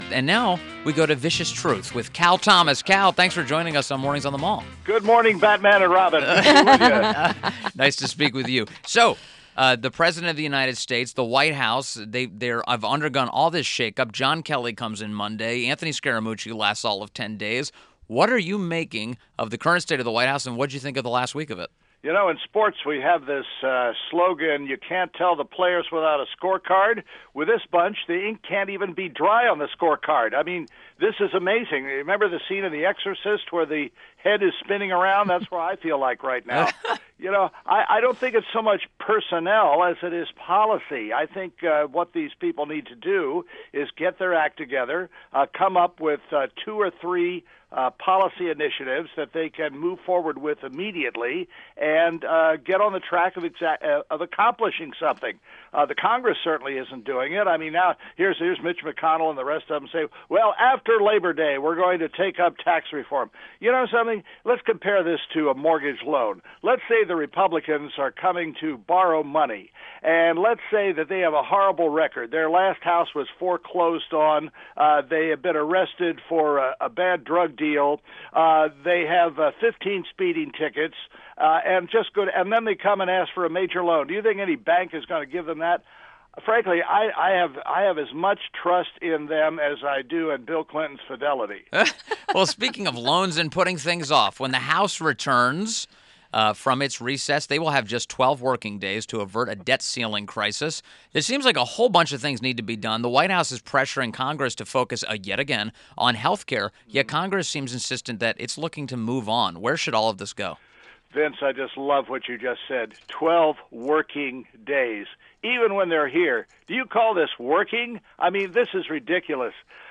INTERVIEW -- CAL THOMAS – Syndicated columnist